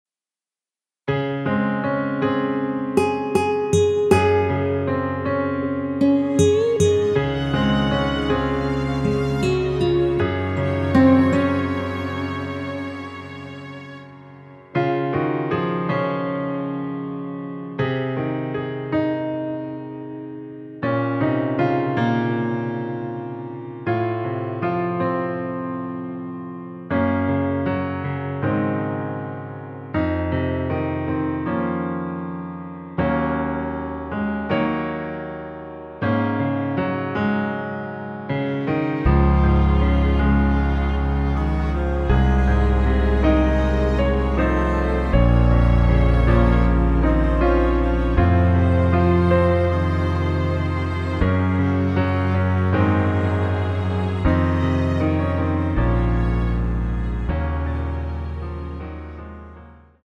원키에서(-7)내린 MR입니다.
Db
앞부분30초, 뒷부분30초씩 편집해서 올려 드리고 있습니다.
중간에 음이 끈어지고 다시 나오는 이유는